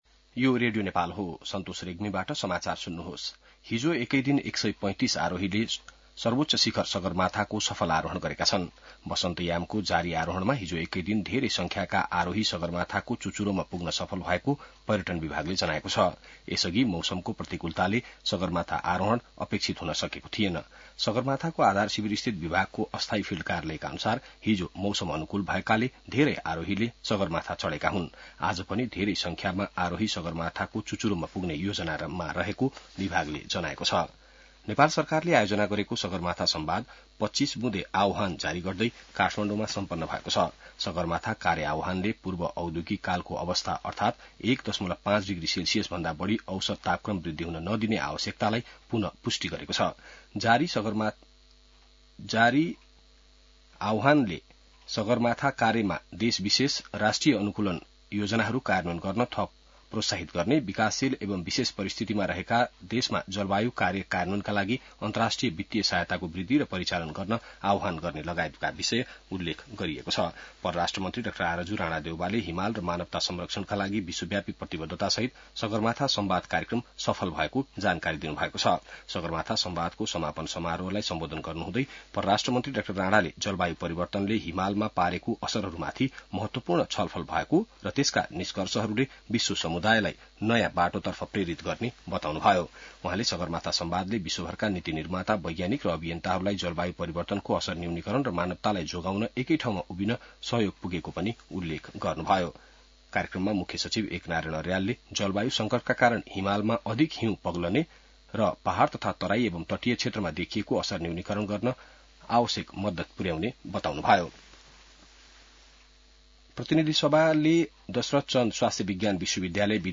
बिहान ६ बजेको नेपाली समाचार : ५ जेठ , २०८२